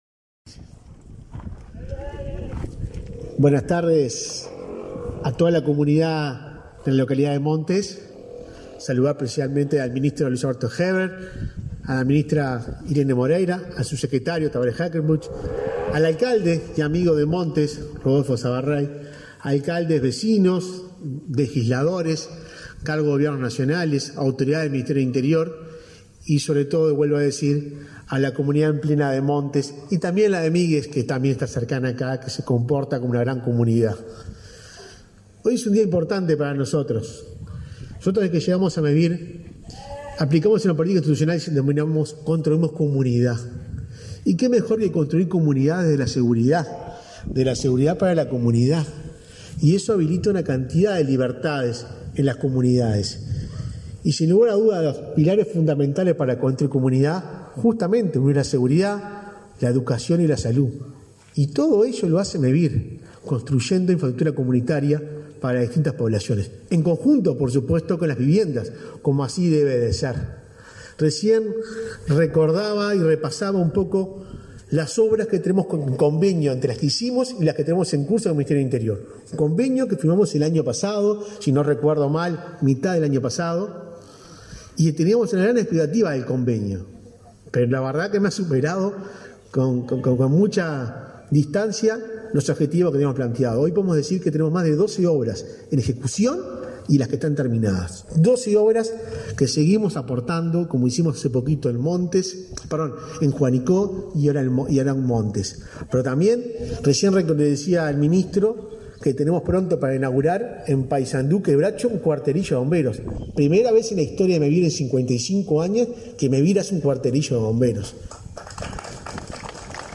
Conferencia de prensa por inauguración de obras en subcomisaría en Montes, en Canelones
Conferencia de prensa por inauguración de obras en subcomisaría en Montes, en Canelones 17/10/2022 Compartir Facebook X Copiar enlace WhatsApp LinkedIn El Ministerio del Interior y Mevir inauguraron, este 17 de octubre, obras en la subcomisaría de la localidad de Montes, en el departamento de Canelones. Participaron en el evento el ministro del Interior, Luis Alberto Heber; la ministra de Vivienda y Ordenamiento Territorial, Irene Moreira; el presidente de Mevir, Juan Pablo Delgado, y el alcalde de Montes, Roberto Salvarrey.